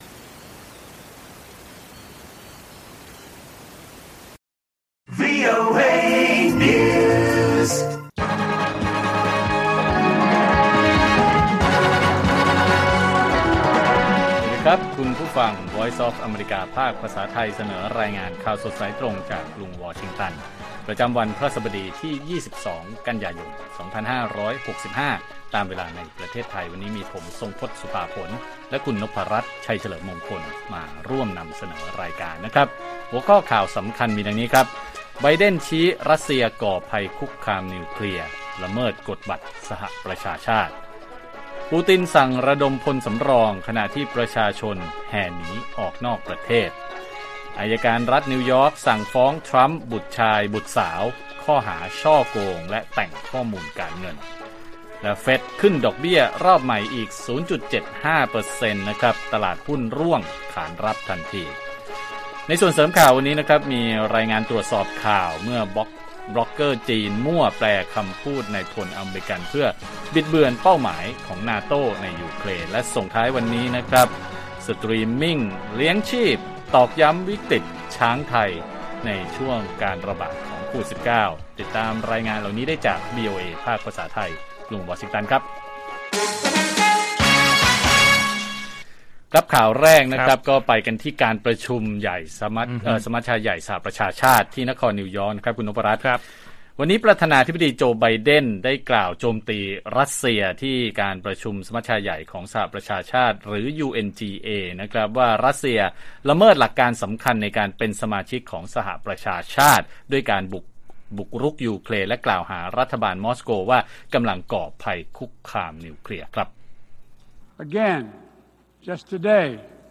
ข่าวสดสายตรงจากวีโอเอไทย 6:30 – 7:00 น. วันที่ 22 ก.ย. 65